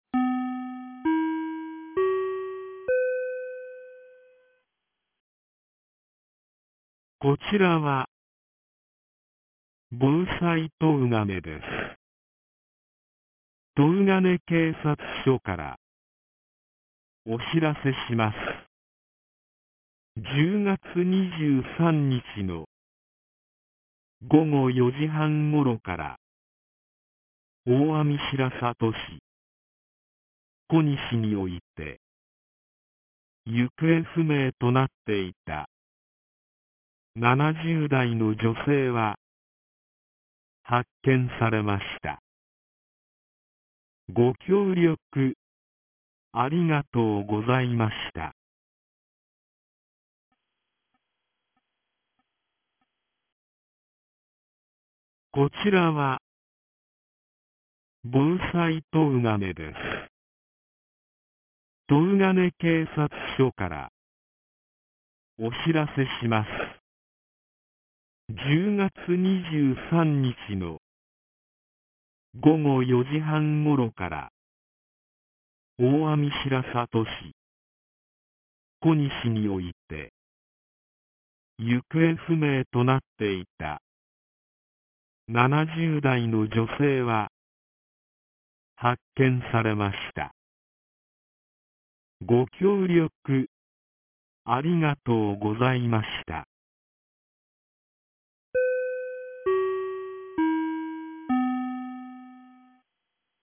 2025年10月27日 15時04分に、東金市より防災行政無線の放送を行いました。